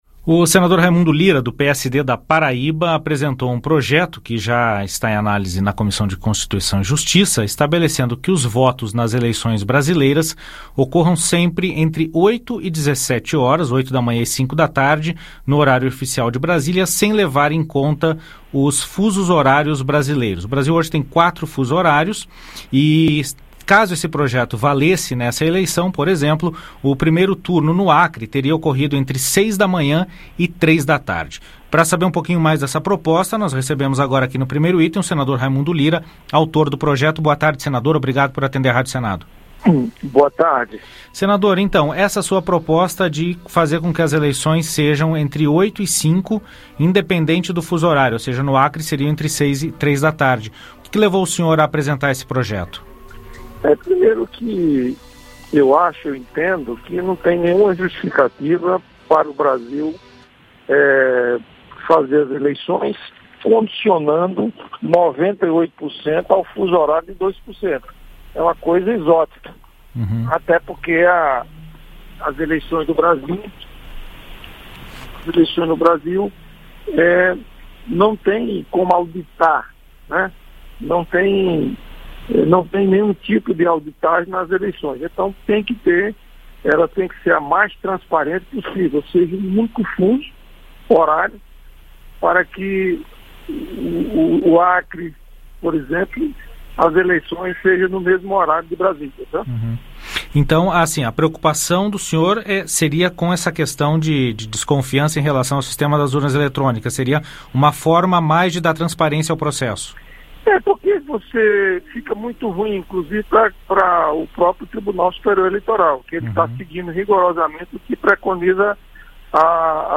conversa com o senador Raimundo Lira (PSD-PB) sobre projeto em análise na Comissão de Constituição e Justiça (CCJ) que pretende unificar o horário das eleições gerais em todo o país, independente de fuso horário.